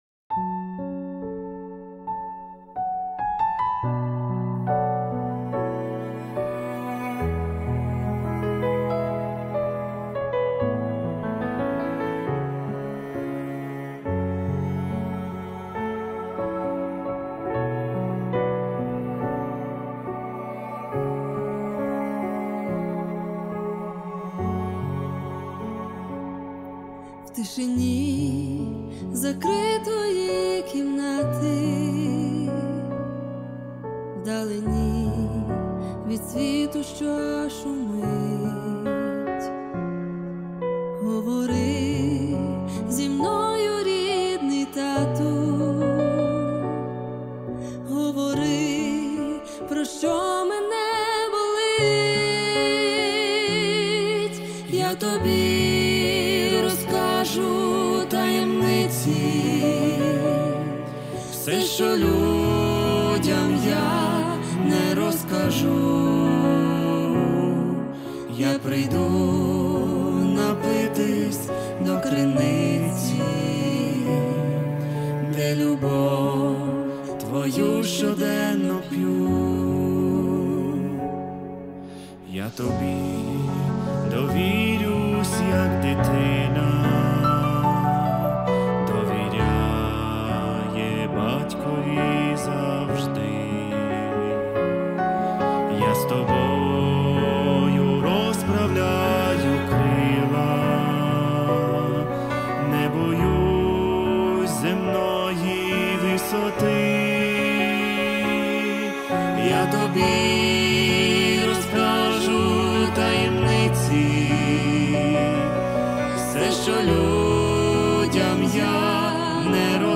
2574 просмотра 681 прослушиваний 348 скачиваний BPM: 70